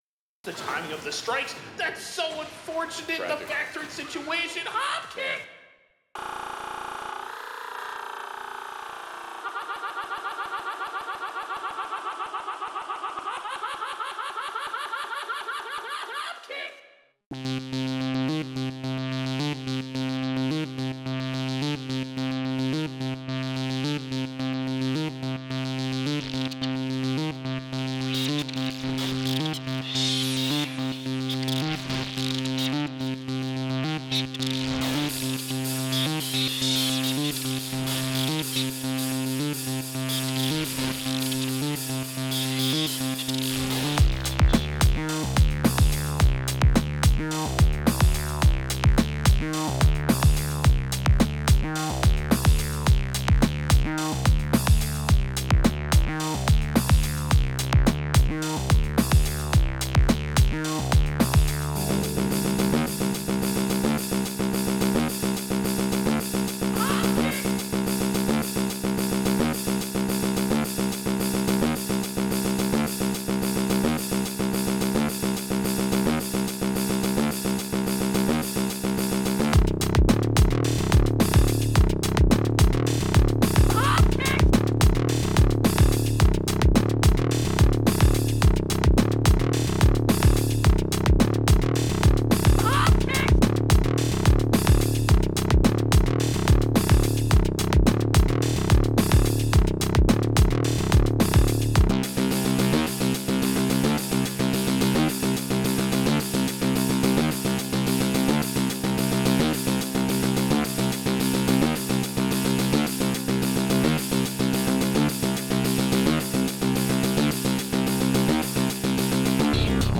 And a shorter separate video for a groove I made: